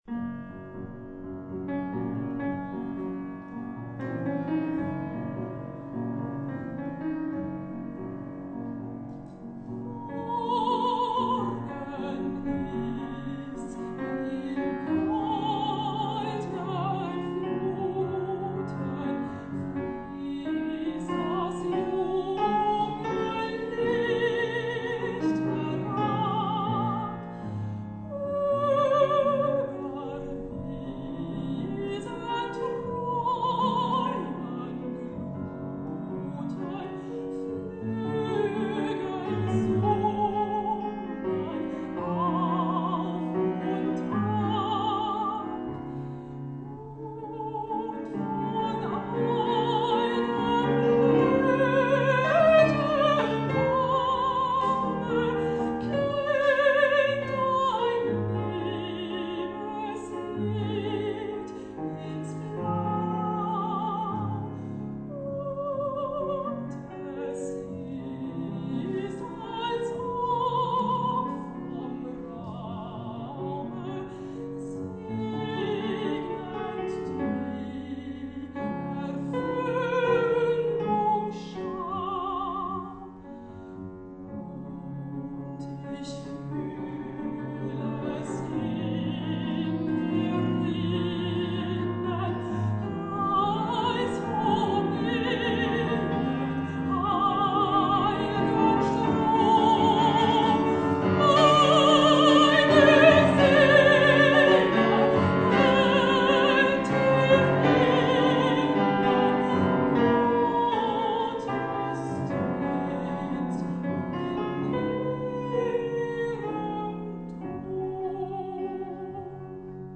Festtag – Day of Celebration, from 1935, in a performance from Karlsruhe Musik Hochschule